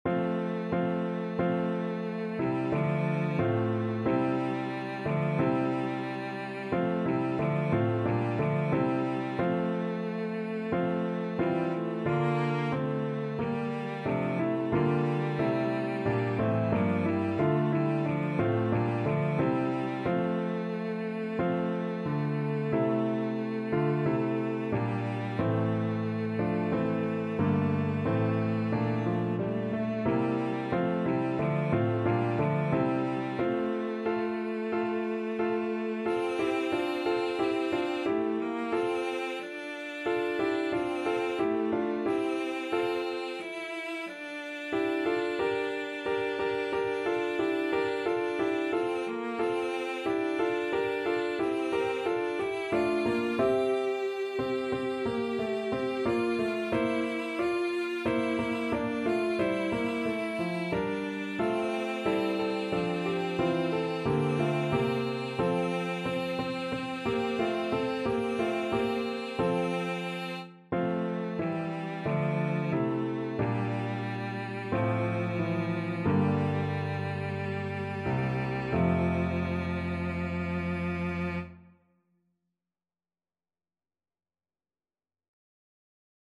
4/4 (View more 4/4 Music)
Classical (View more Classical Cello Music)